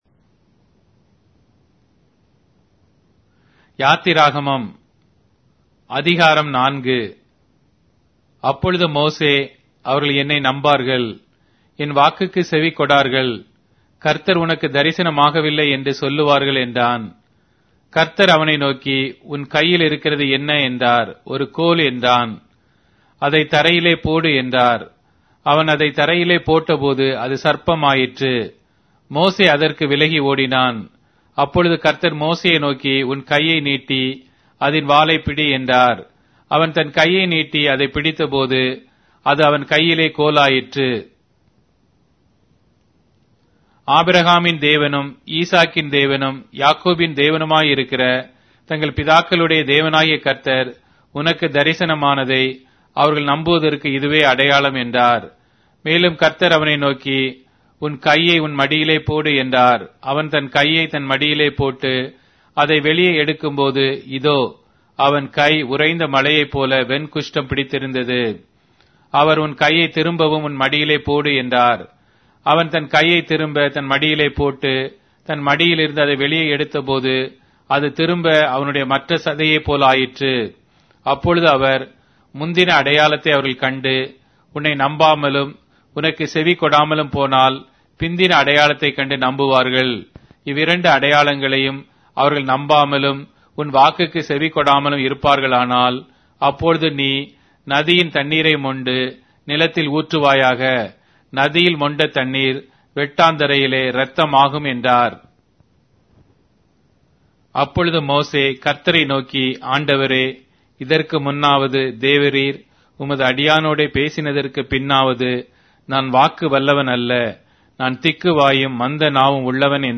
Tamil Audio Bible - Exodus 22 in Irvmr bible version